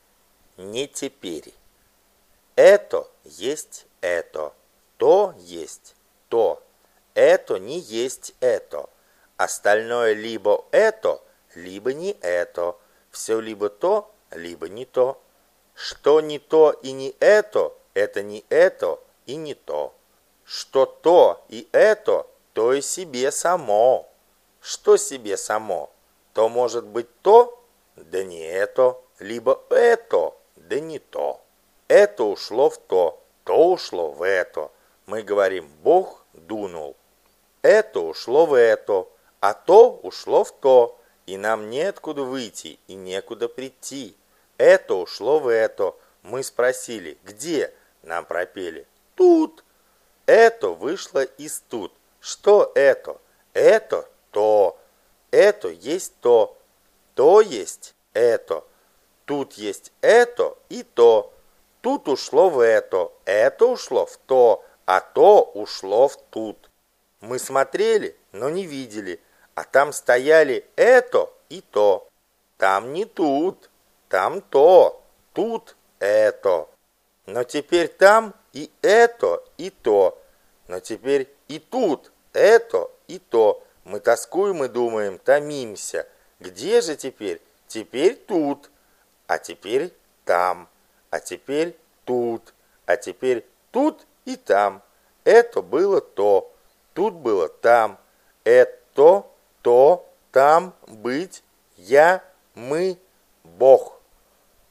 Галерея Хармс 5 часов АУДИОКНИГА
аудиокниги бесплатно